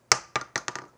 shotgun-shell-ejection-4.wav